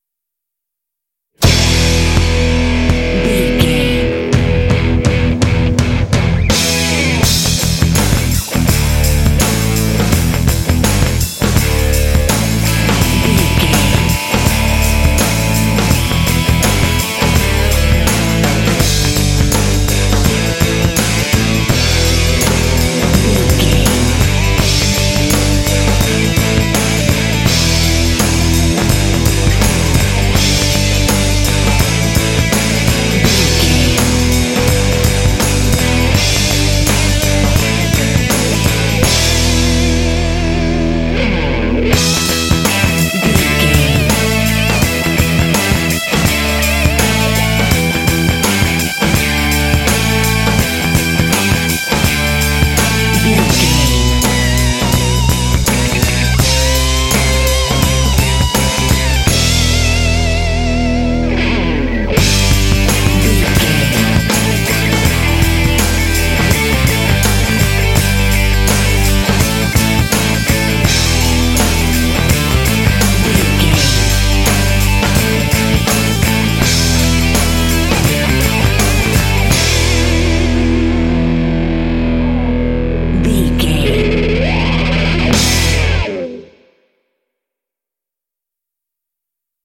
Ionian/Major
powerful
driving
energetic
bright
electric guitar
bass guitar
drums
rock
hard rock
metal